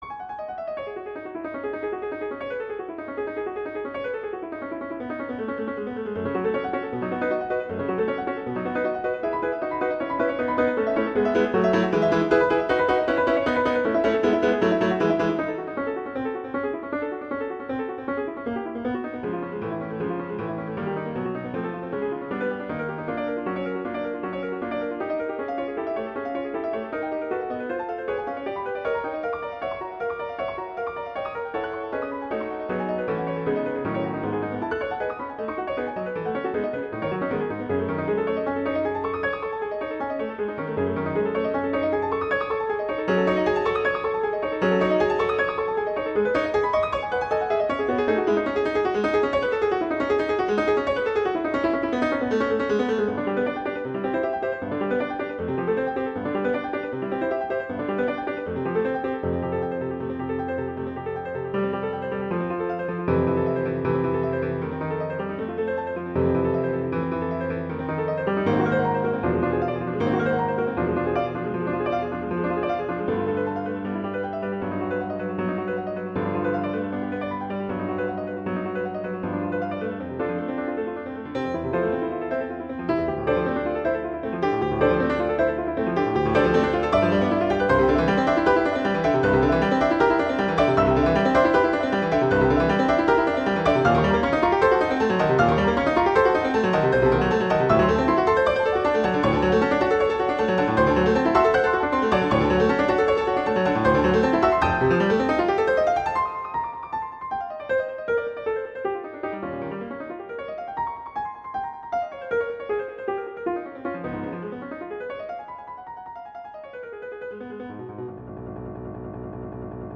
Instrumentation: piano solo
classical, french
E major
♩=156 BPM (real metronome 152 BPM)